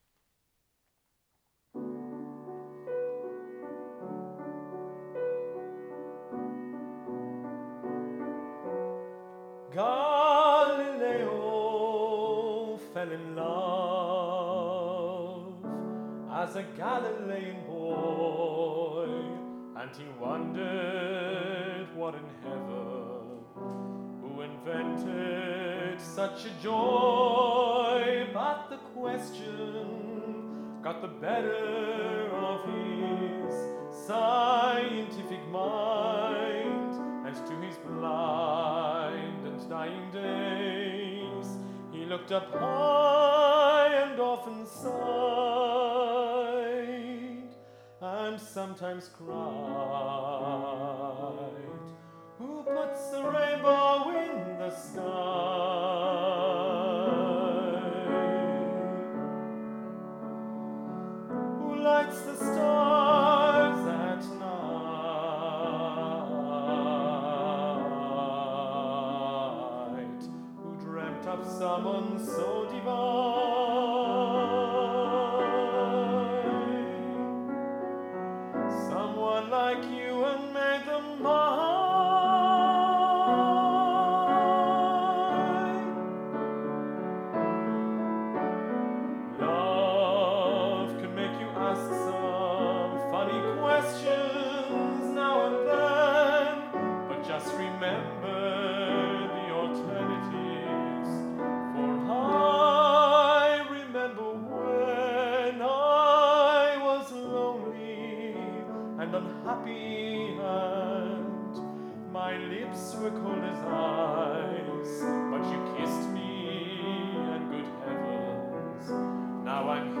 with Piano